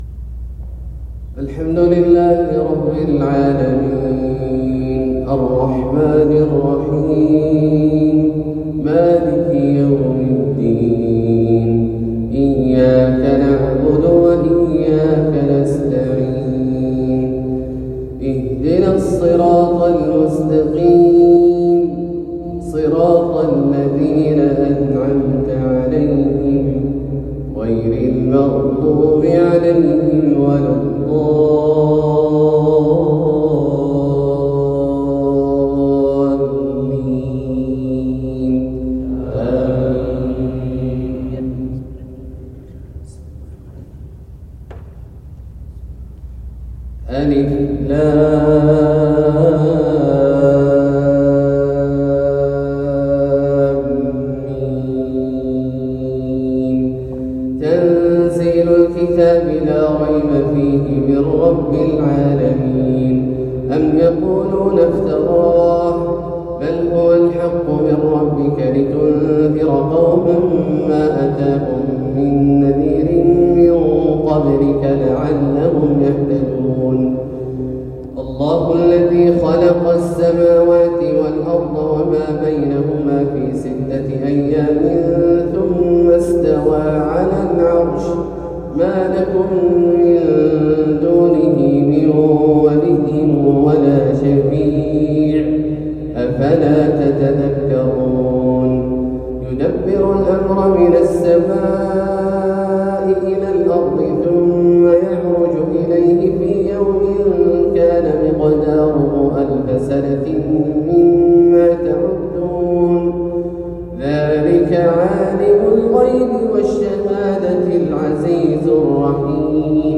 فجر الجمعة 23 محرم 1447هـ من جامع الملك عبدالله بخميس مشيط > زيارة فضيلة الشيخ أ.د. عبدالله الجهني للمنطقة الجنوبية | محرم 1447هـ > المزيد - تلاوات عبدالله الجهني